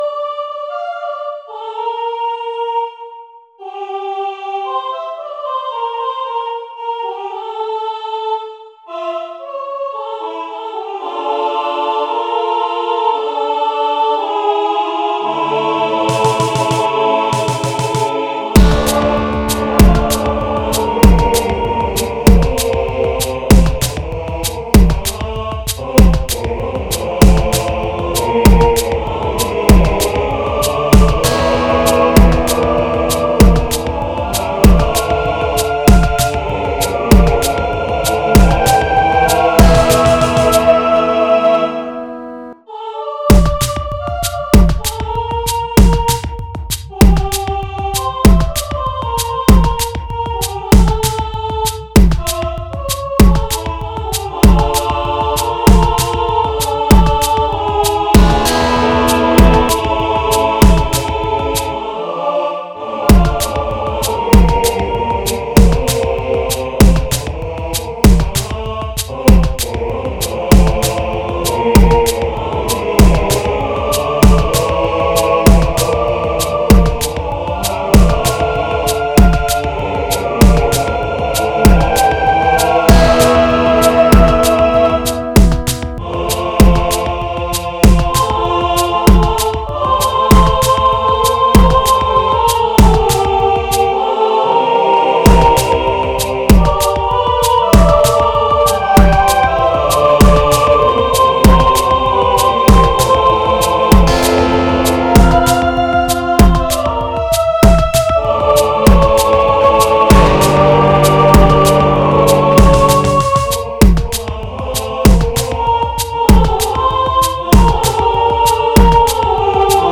Y el Canon anterior ahora arreglado con ritmo moderno y un toque re rock